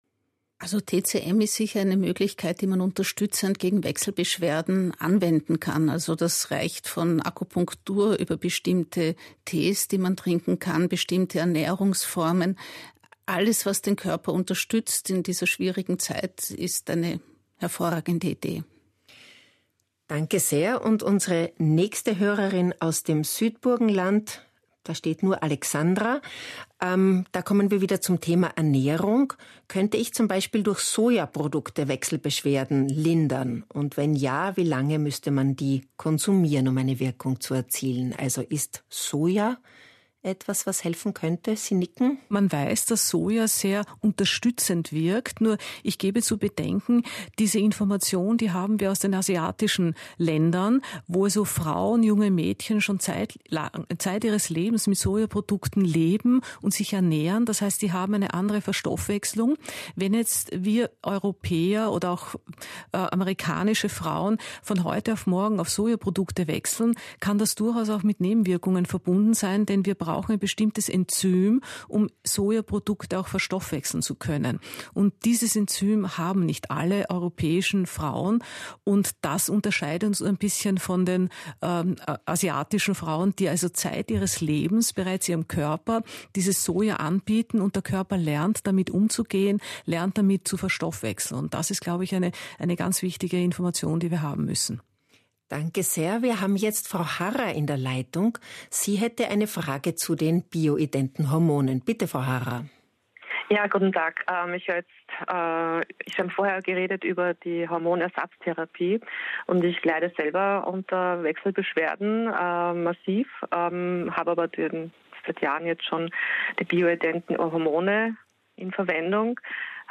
Radiobeitrag Ö1, vom 17.10.2024: Menopausetalk Teil 1